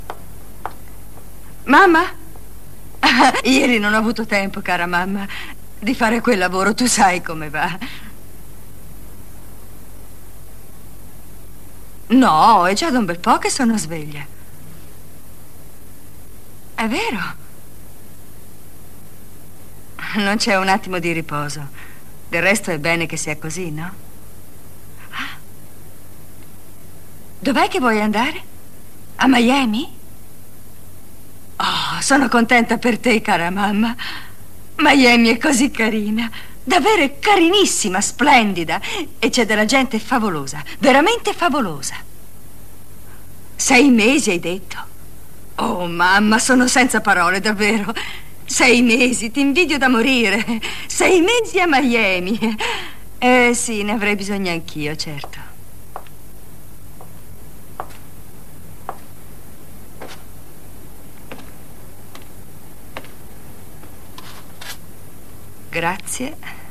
FILM CINEMA